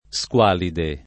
[ S k U# lide ]